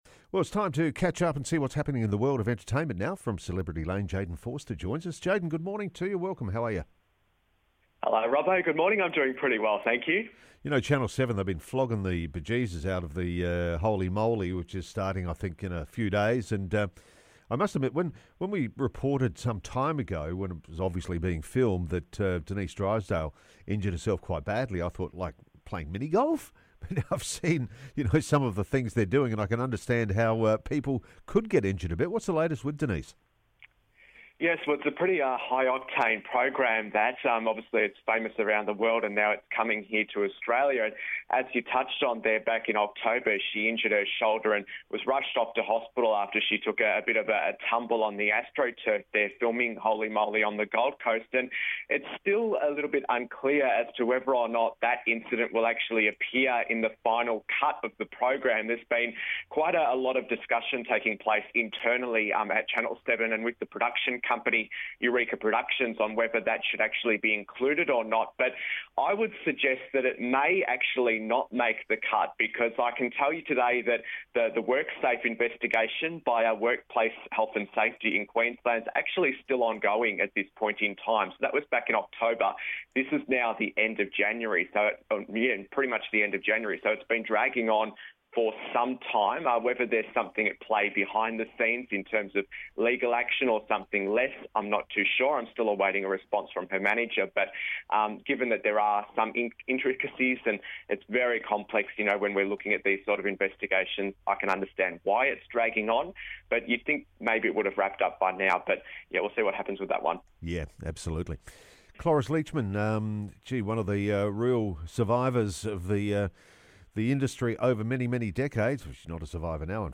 Entertainment reporter